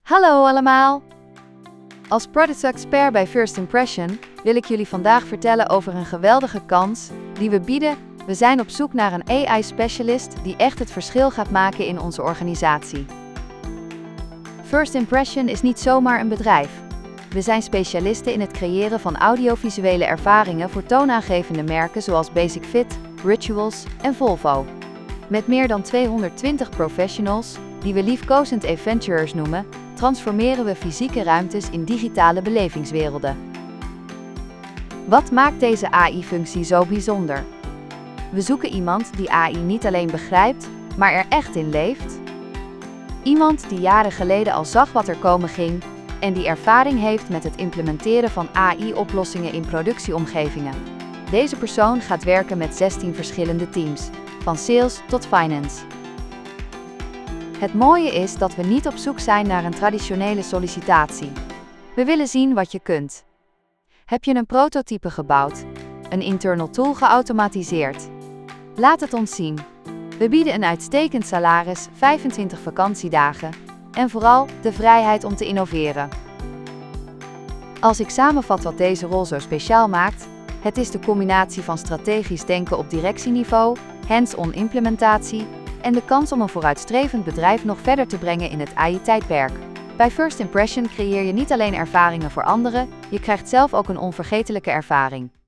Podcast gegenereerd van tekst content (4545 karakters)